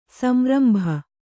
♪ samrambha